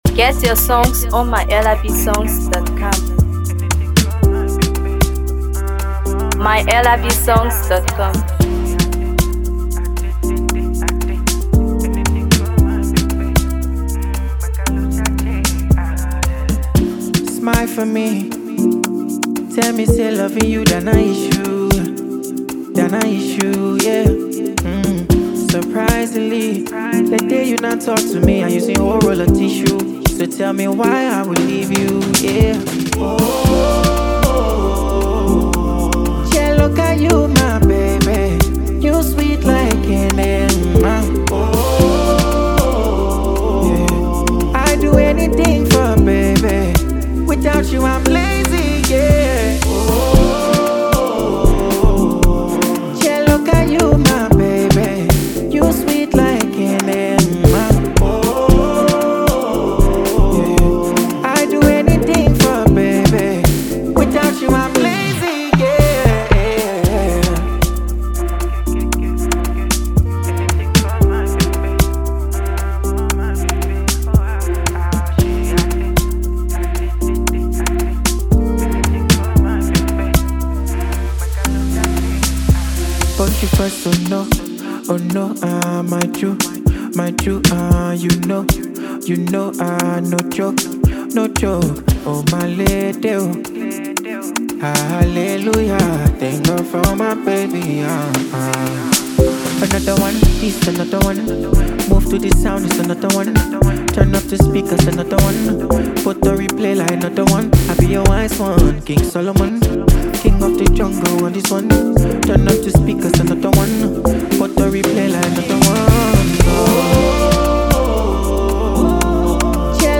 Afro PopHipcoMusic
love song
smooth melodies and heartfelt lyrics